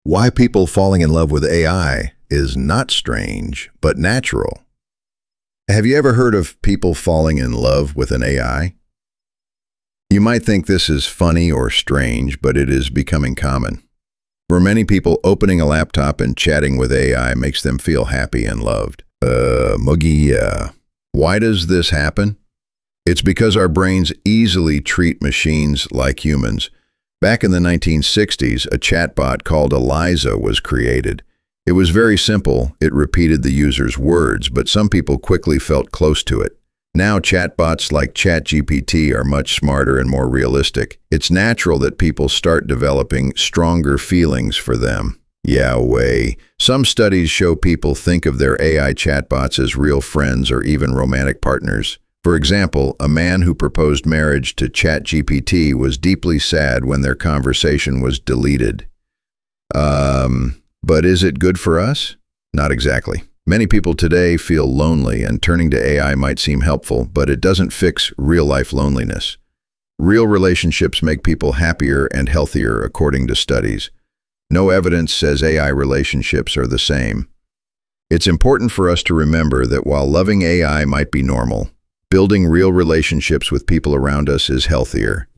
＜音読用音声＞